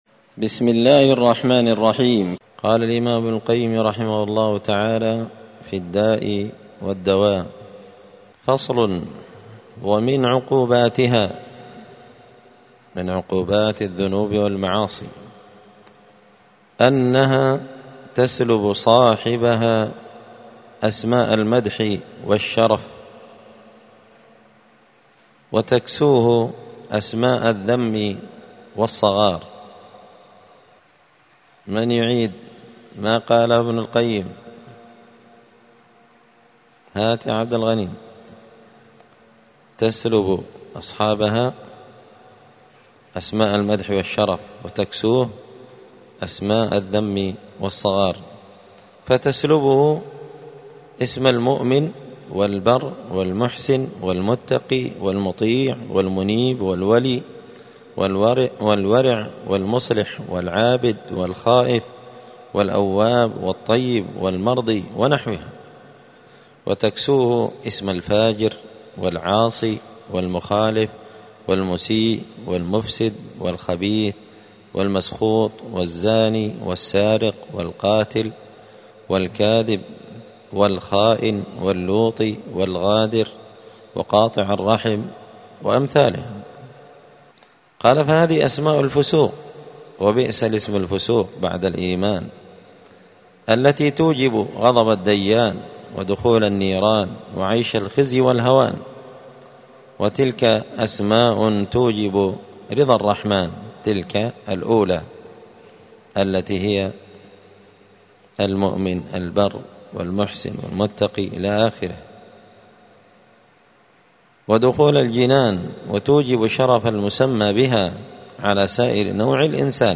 الجمعة 13 شعبان 1445 هــــ | الداء والدواء للإمام ابن القيم رحمه الله، الدروس، دروس الآداب | شارك بتعليقك | 57 المشاهدات
دار الحديث السلفية بمسجد الفرقان بقشن المهرة اليمن